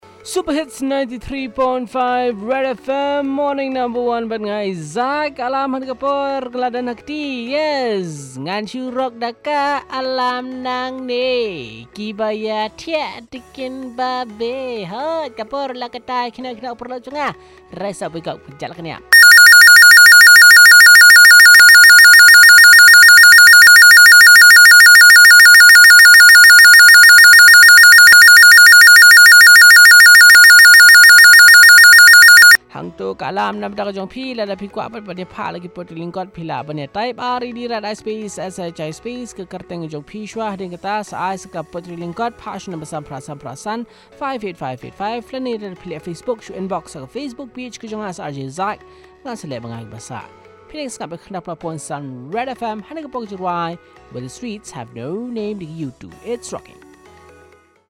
wakes up the callers with his alarm and pre sells Short poem